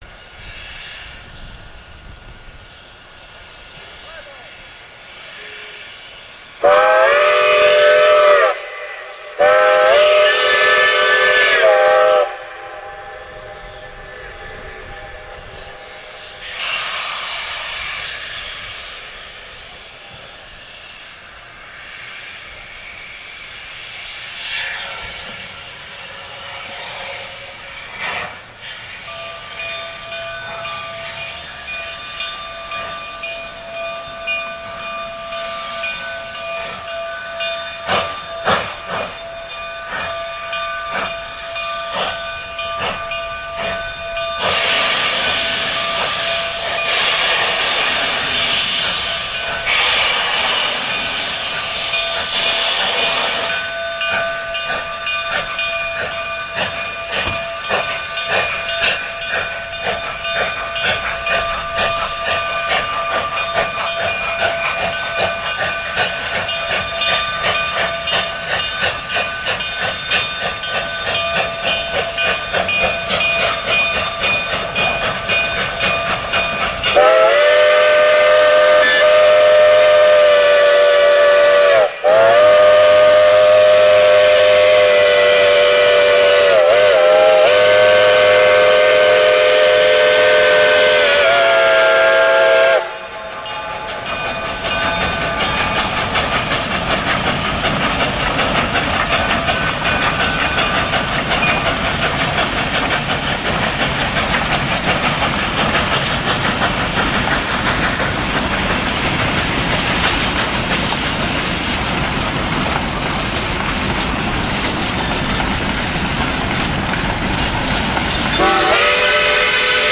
steam loco